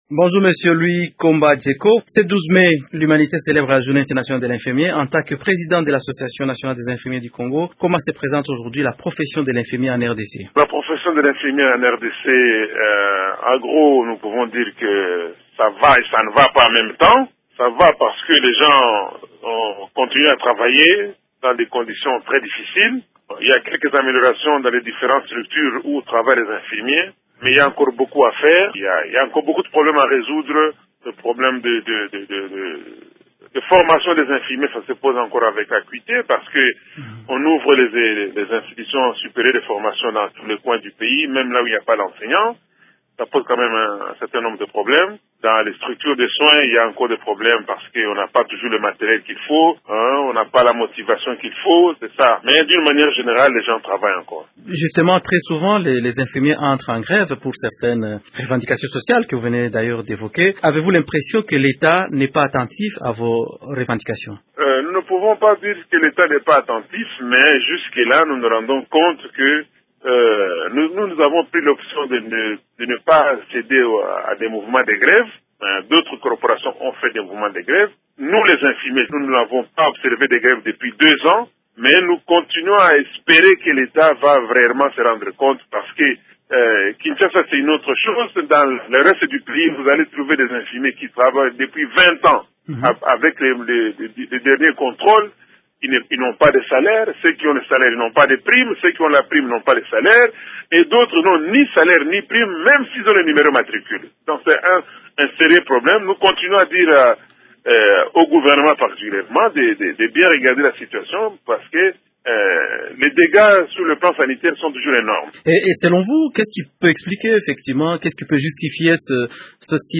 est interrogé